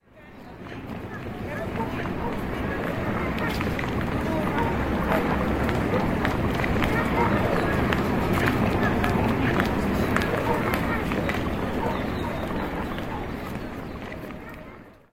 CityStreet